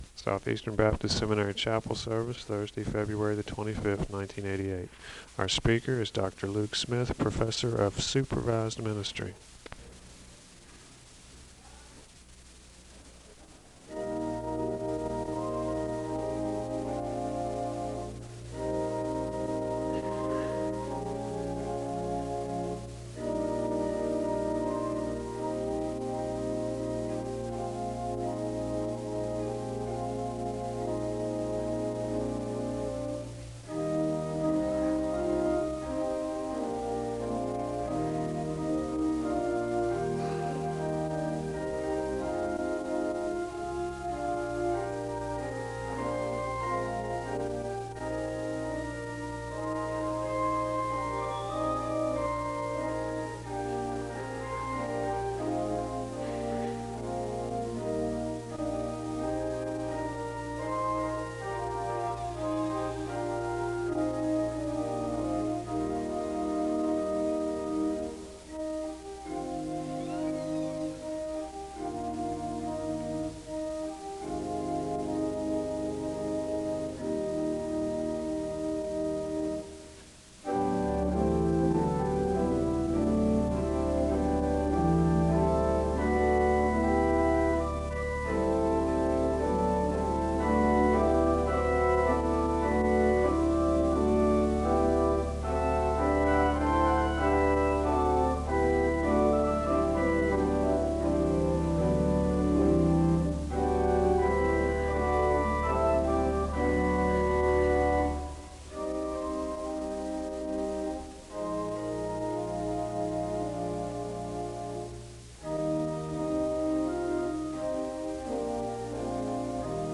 The service begins with organ music (0:00-5:00). There are Scripture readings (5:01-7:33).
There is a moment of prayer (7:34-9:34).
The service closes with a benediction (27:01-27:23).